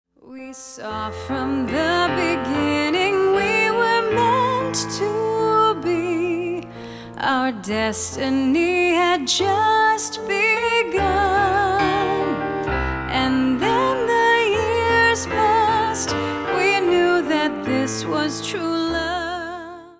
Piano
Vocals